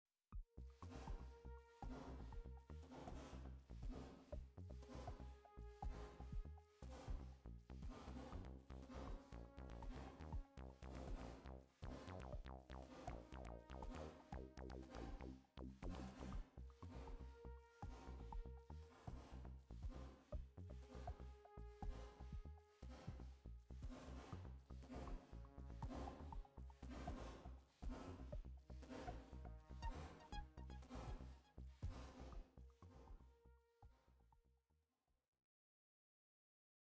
Swarm only. 6 machines. I couldn’t for the life of me get a good snare/ click percussion or hat sound.